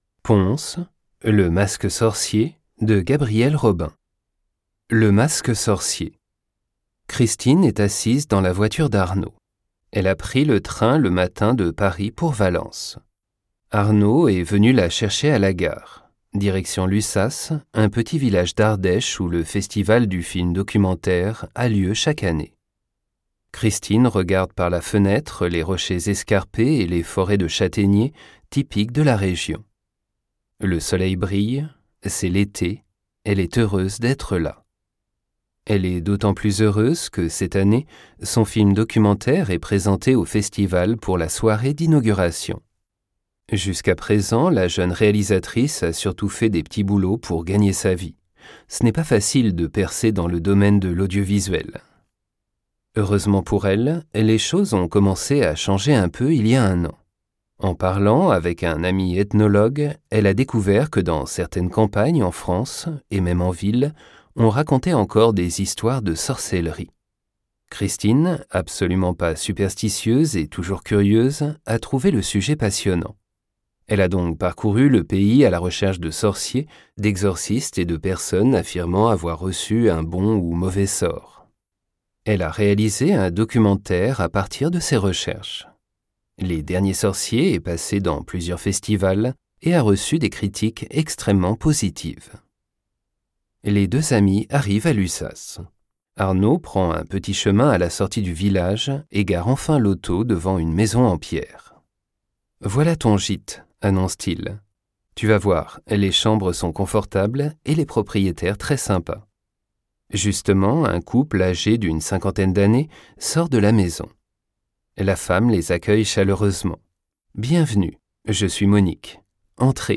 Französisch lernen mit mörderischen Kurzgeschichten - Spannende Kurzkrimis zum Sprachenlernen. - Vorgelesen von einem muttersprachlichen Profi-Sprecher in einem für den Lerner passenden Tempo.